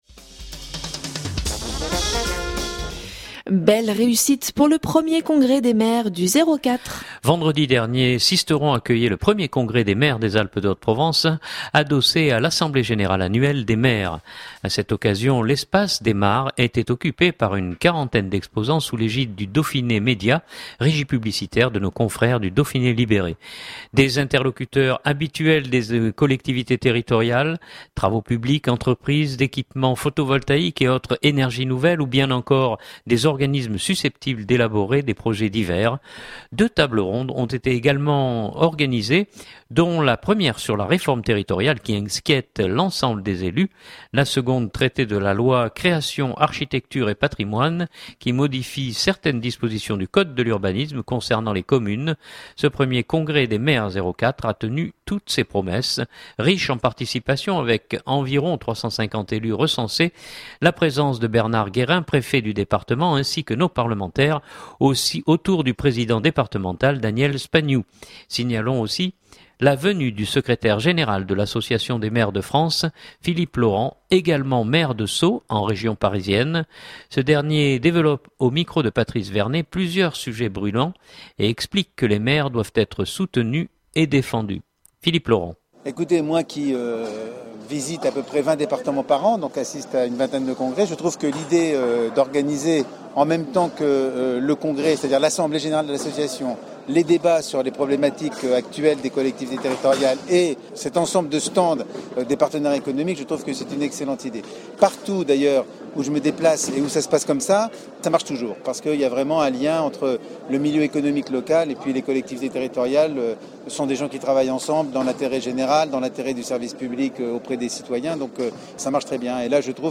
Vendredi dernier, Sisteron accueillait le premier congrès des maires des Alpes de Haute-Provence adossé à l’assemblée générale annuelle des maires.
Signalons aussi la venue du Secrétaire Général de l’Association des maires de France, Philippe Laurent, également maire de Sceaux en région parisienne.